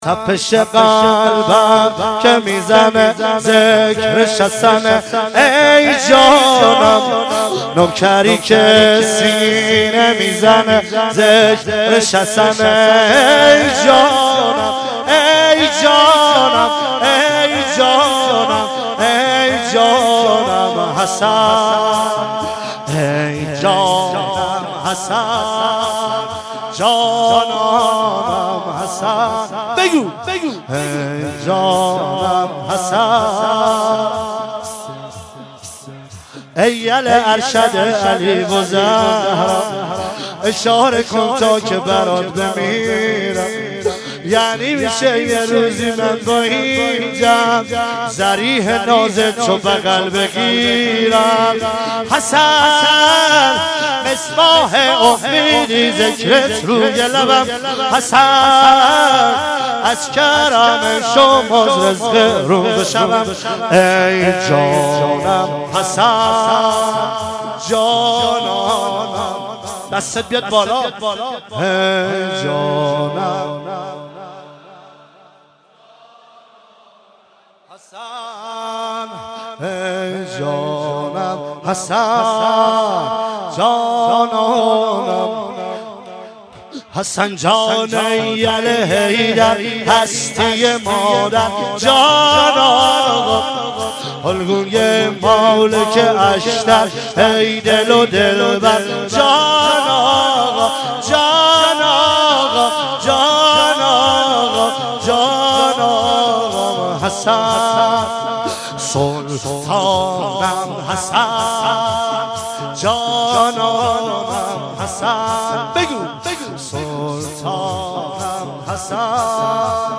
مراسم فاطمیه اول ۹۶
شور